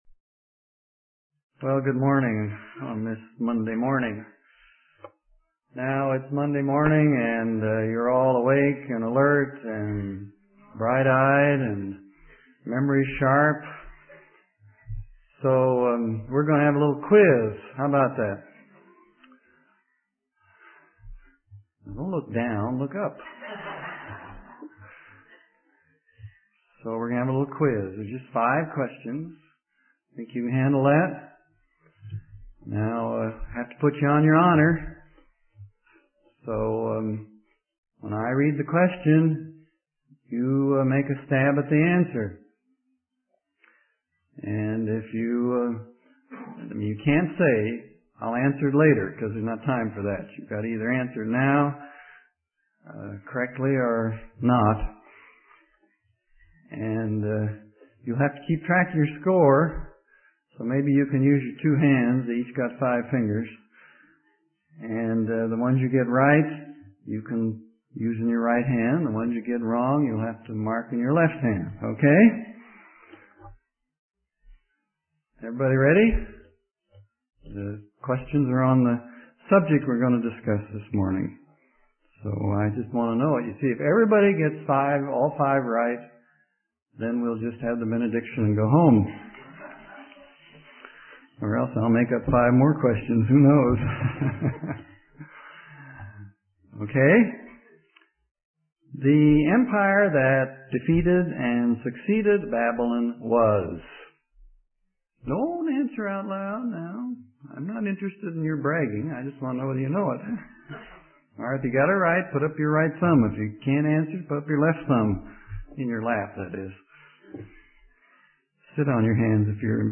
In this sermon, the speaker discusses a dream that Nebuchadnezzar had, which is described in the book of Daniel. The dream involves an image with six parts, representing different kingdoms throughout history.